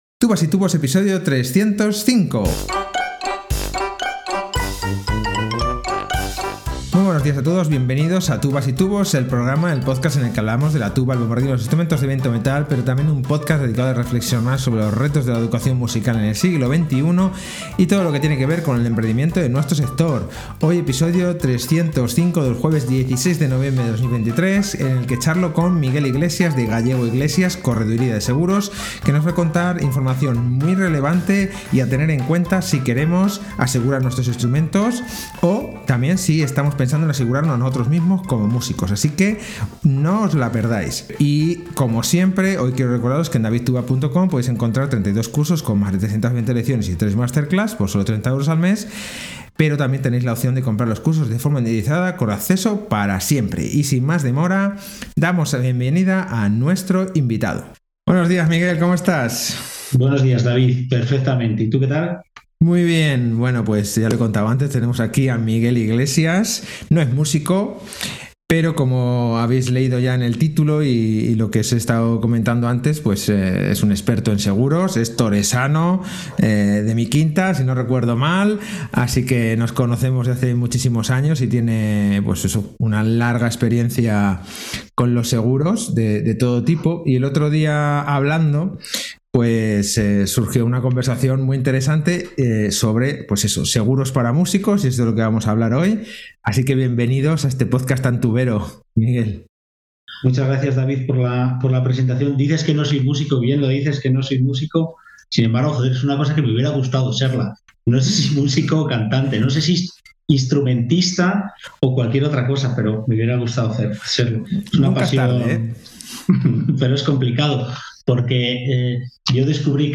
Hoy entrevistamos